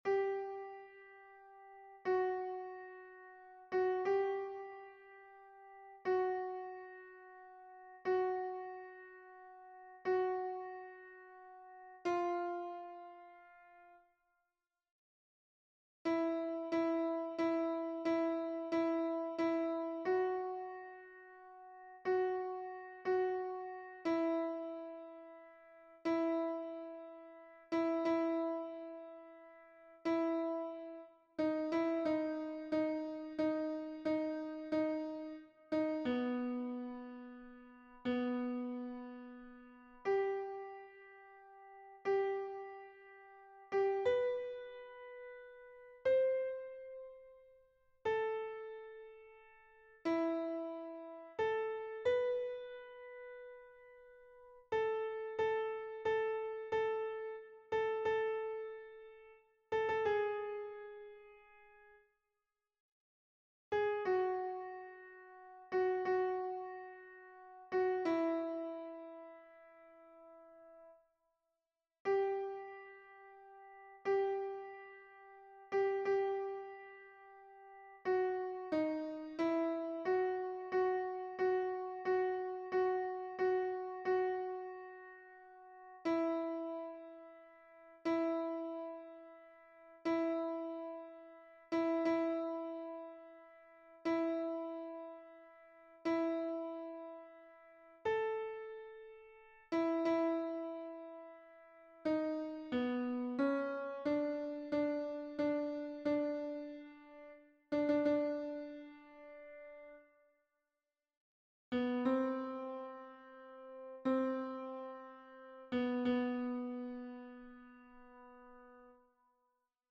MP3 versions rendu voix synth.
Alto